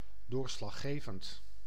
Ääntäminen
IPA: /sɑ̃t.ʁal/